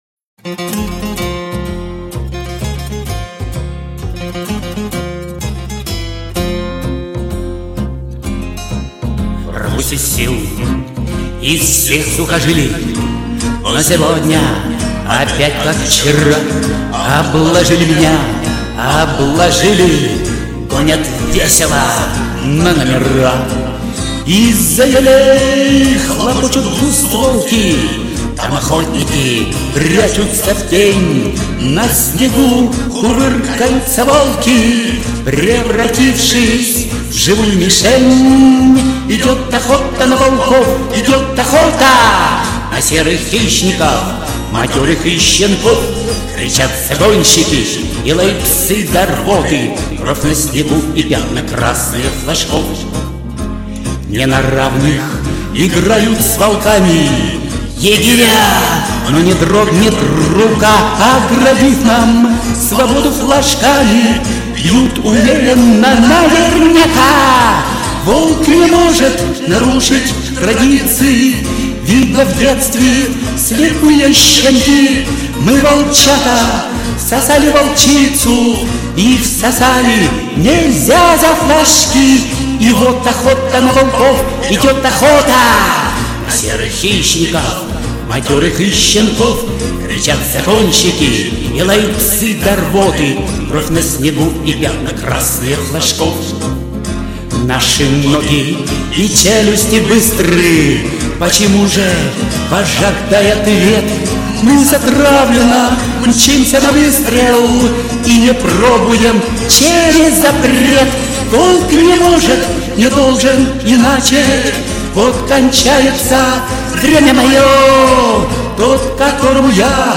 Комментарий инициатора: Только под гитару.